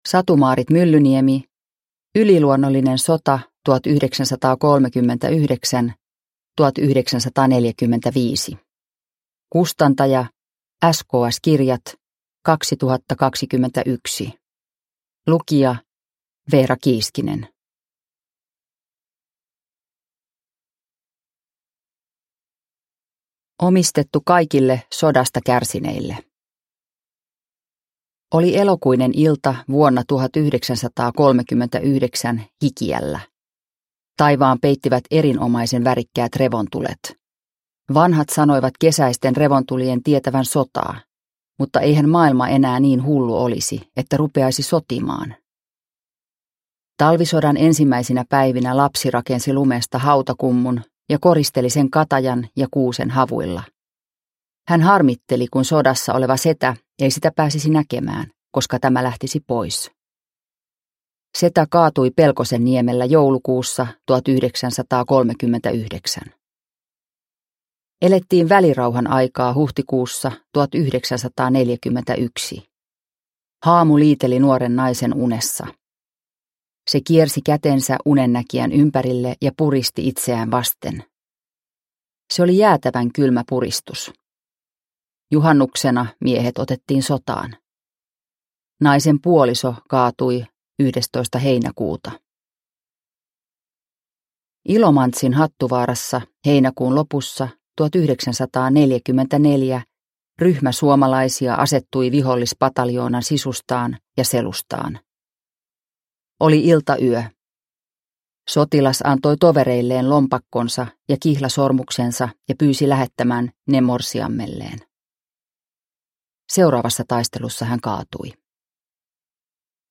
Yliluonnollinen sota 1939-1945 – Ljudbok